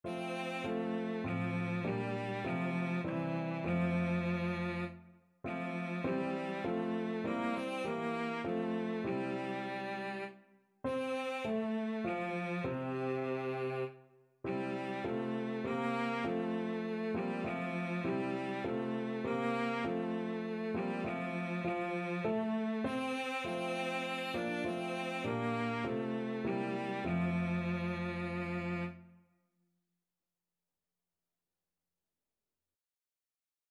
Christian Christian Cello Sheet Music Come, Thou Almighty King
Cello
F major (Sounding Pitch) (View more F major Music for Cello )
3/4 (View more 3/4 Music)
Classical (View more Classical Cello Music)